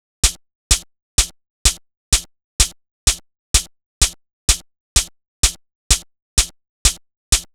Chh Loop Ghetto.wav